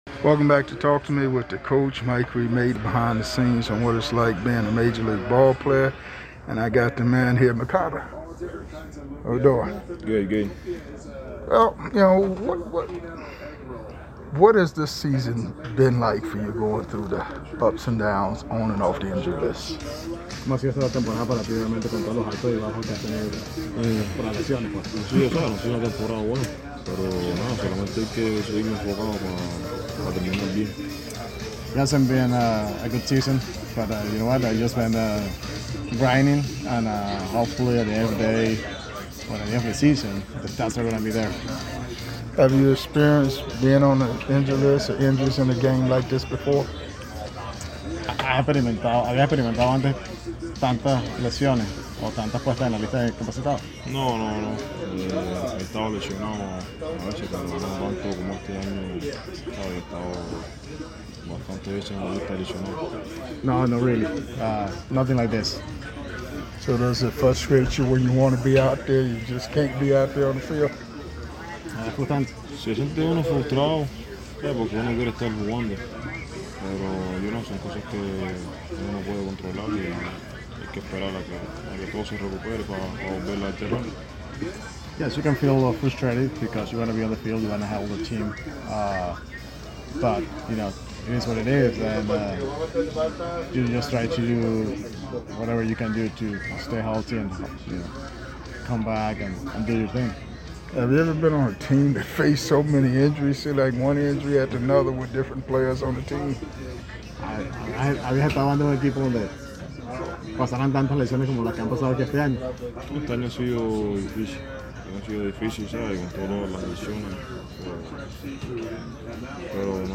Taking you behind the scenes full uncut and unedited MLB interviews with past and present players